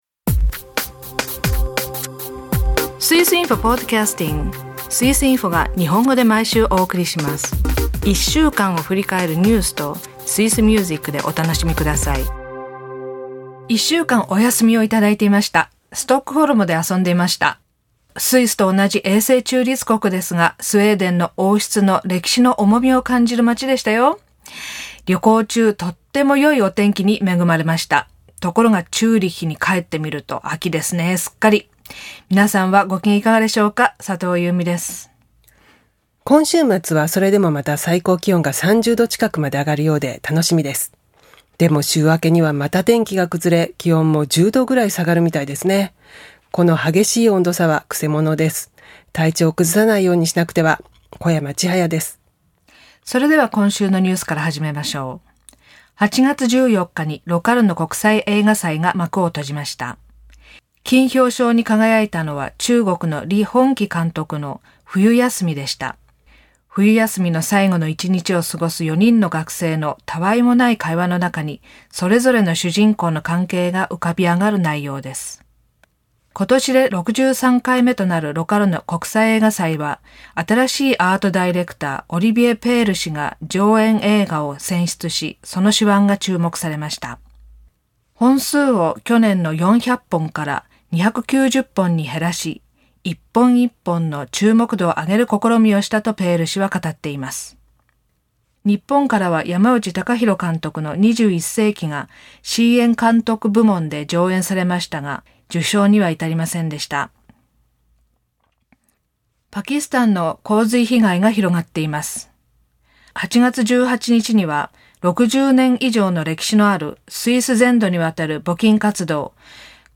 アルプホルンの1曲をお届けします。朗読では、戦争のきな臭さが濃くなってきます。